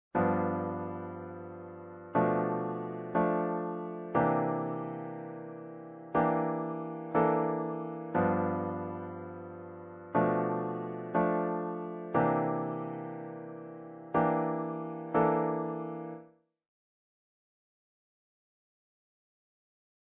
And always good for chord voicings to give the bass its space :) These last ideas use inversions to get us off the root pitch of the chords, to lighten the texture a bit and add some new colors to the harmony, all diatonic.